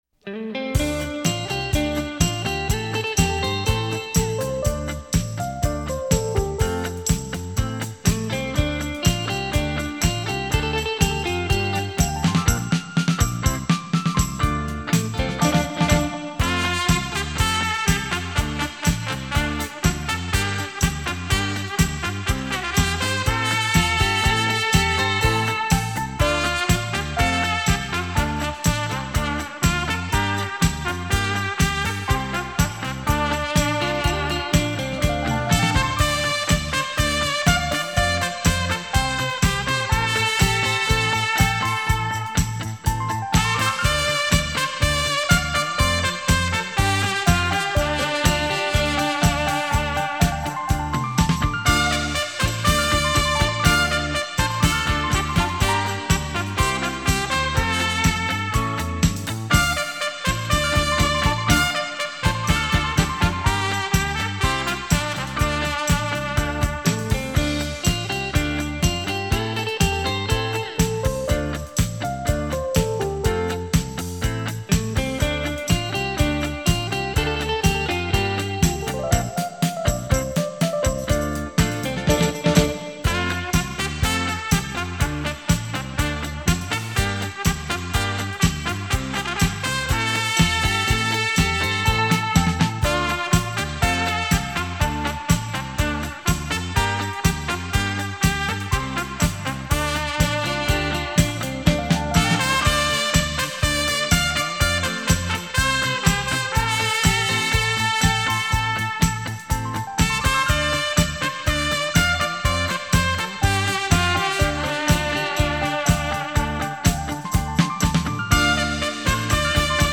24bit 数位录音
闲适优雅的音符，完美传真的音质